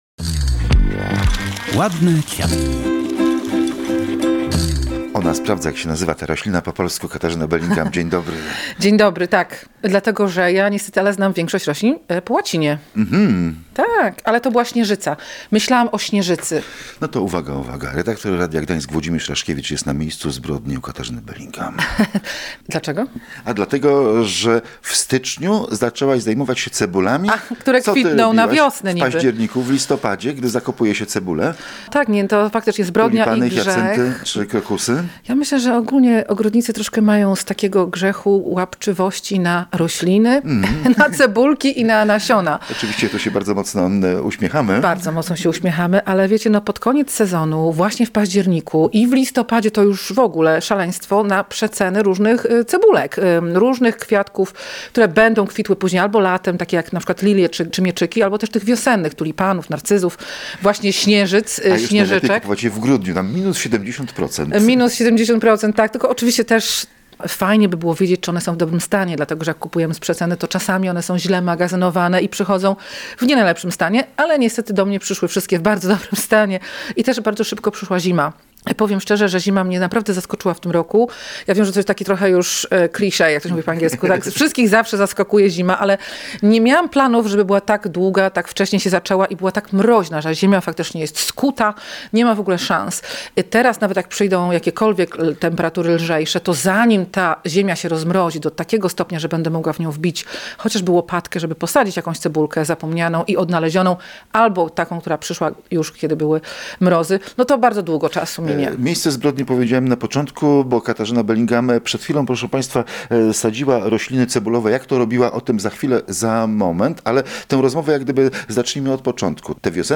Posłuchaj rozmowy inspirującej do zimowego sadzenia cebulowych: